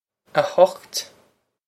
a hukht
This is an approximate phonetic pronunciation of the phrase.